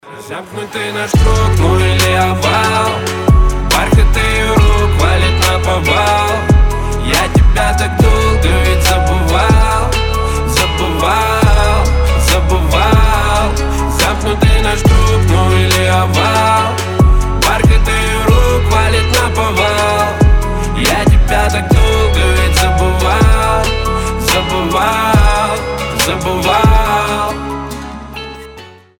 • Качество: 320, Stereo
лирика
грустные
красивый мужской голос